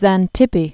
(zăn-tĭpē)